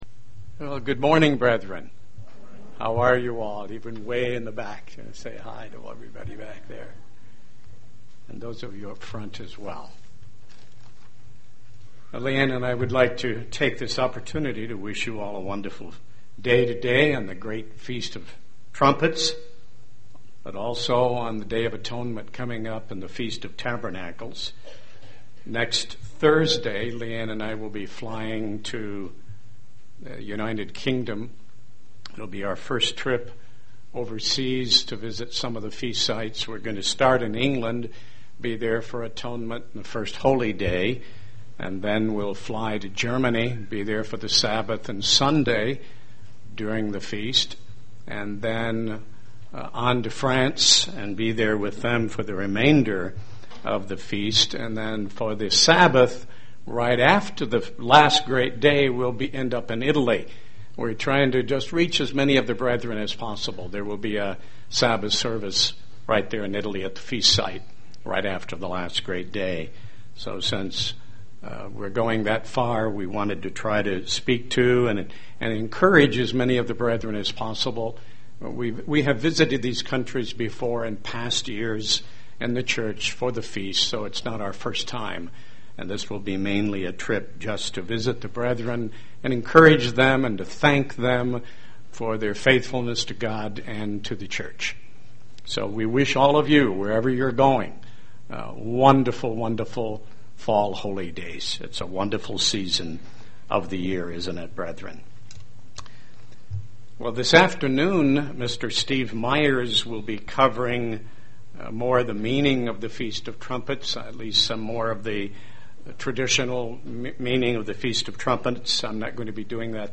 We are instructed to "watch". What does that mean? Feast of Trumpets AM service. 2011
Feast of Trumpets AM service. 2011 UCG Sermon Studying the bible?